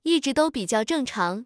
tts_result_9.wav